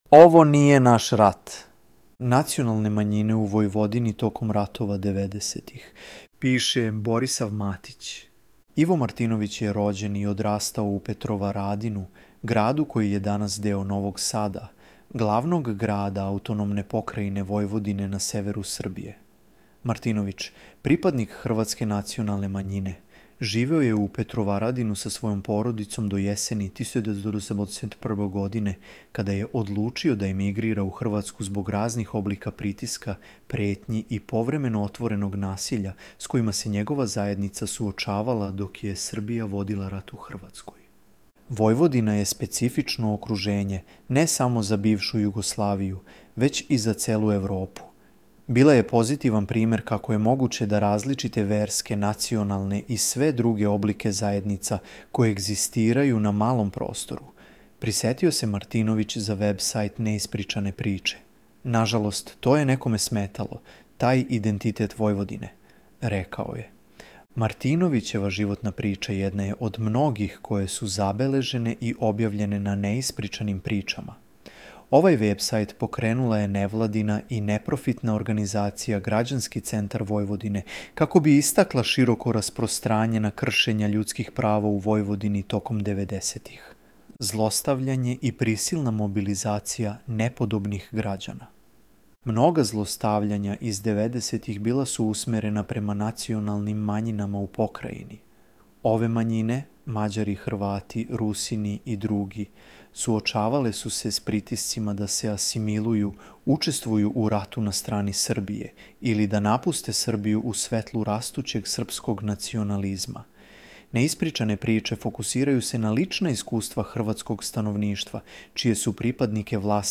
Ispod možete pronaći verziju članka sa glasovnim čitanjem.